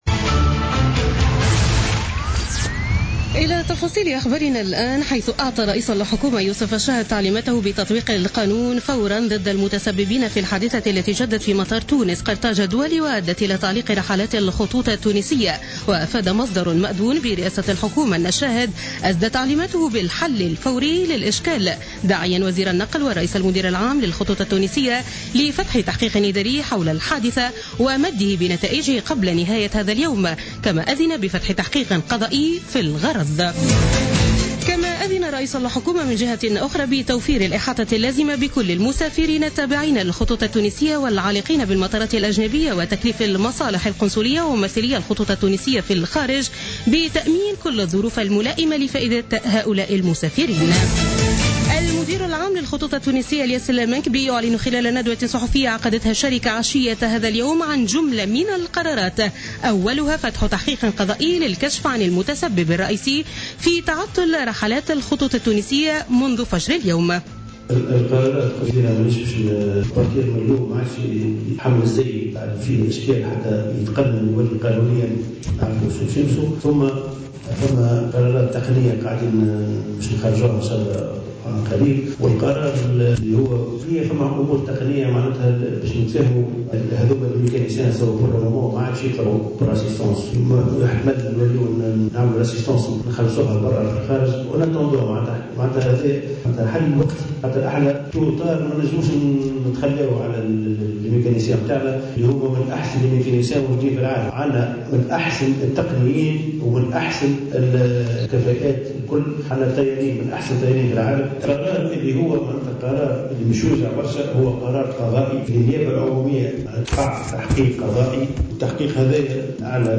نشرة أخبار السابعة مساء ليوم الخميس 9 مارس 2017